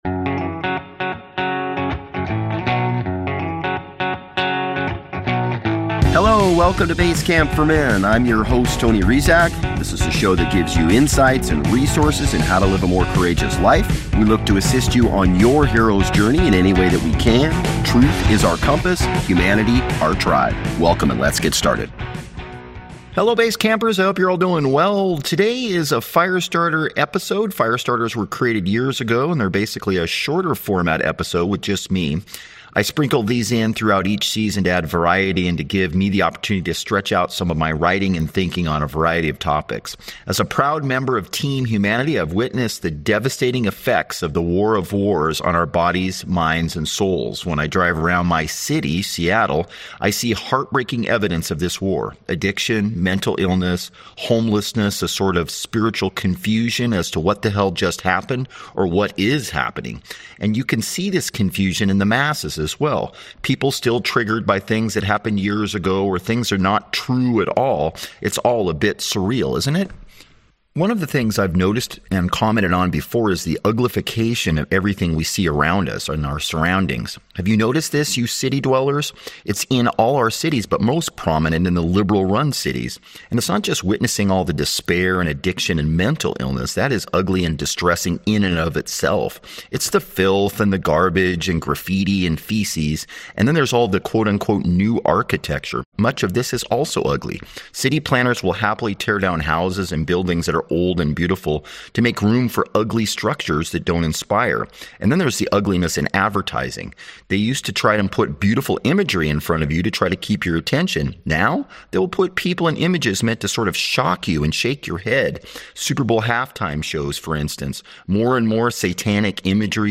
Firestarters were created years ago and they are basically a shorter format episode with just me.